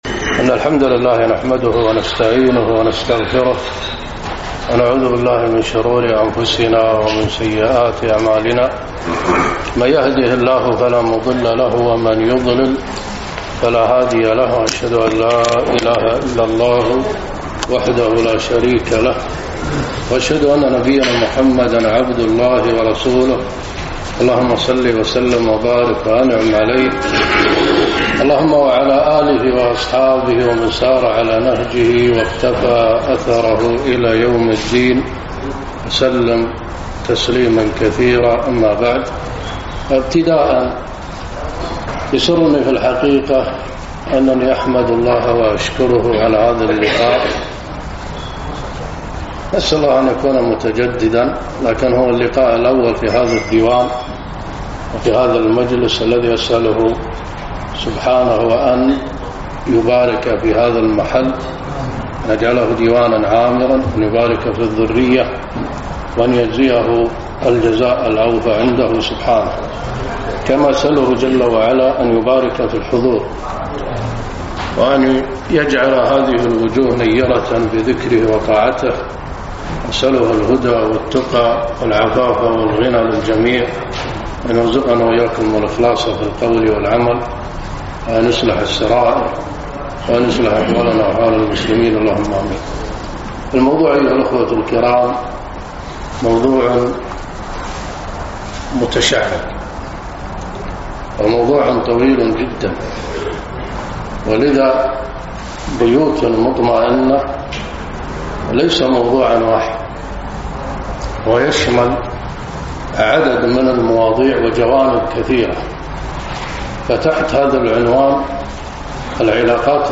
محاضرة - بيوت مطمئنة